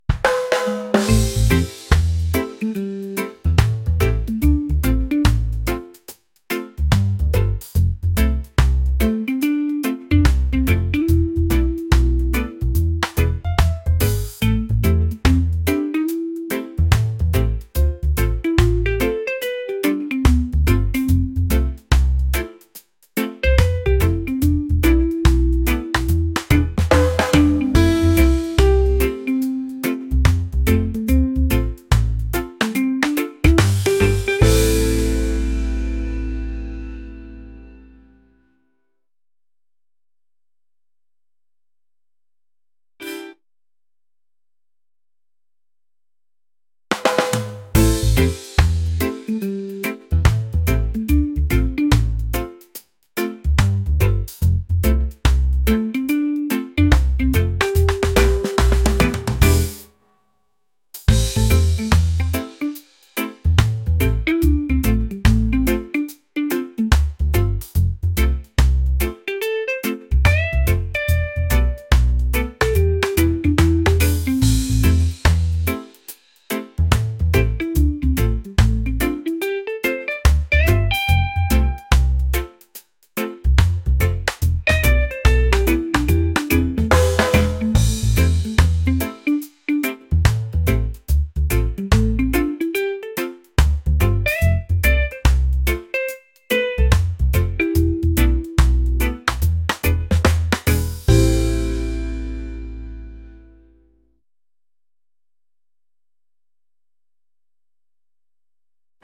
jazz | laid-back | reggae